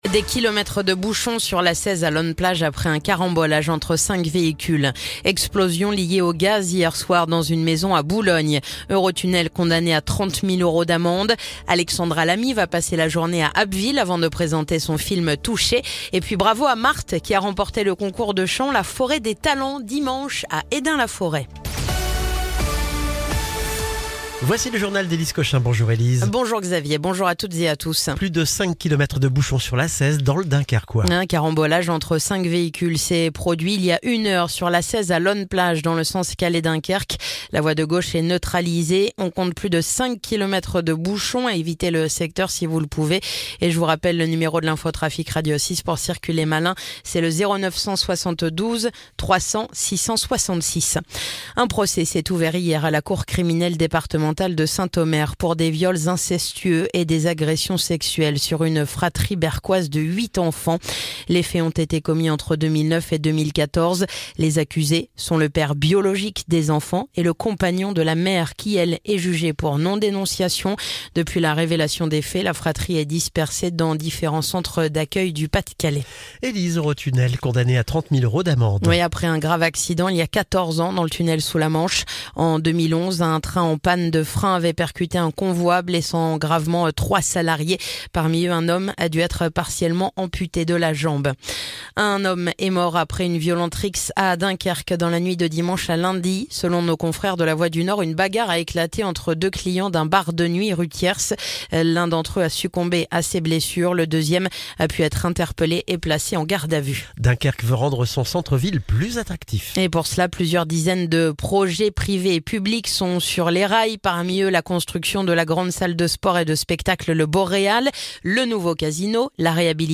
Le journal du mardi 18 mars